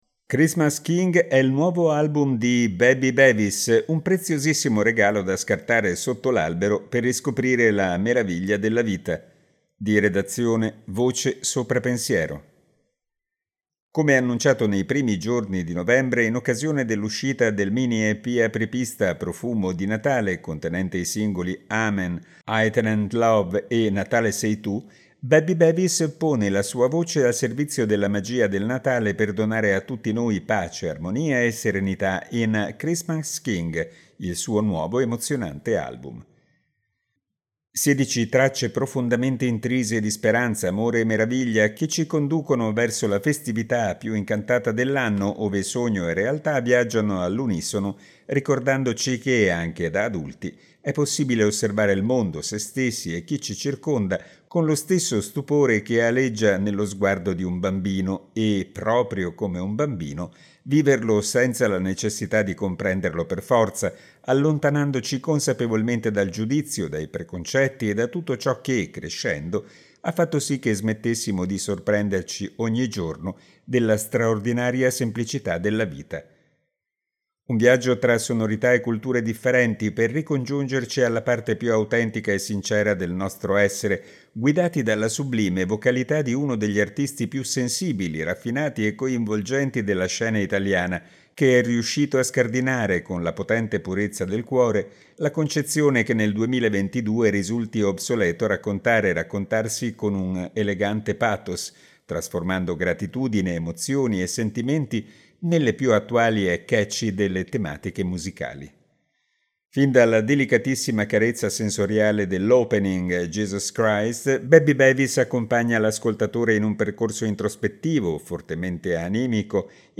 Afrobeat, reggae, pop melodico ed elettronica si susseguono in un disco nato per cantare, ballare e festeggiare: in famiglia, con gli amici, con il proprio partner, ma soprattutto, con quel bambino interiore che abita in ciascuno di noi; un album per celebrare a 360 gradi la meraviglia dell’esserci.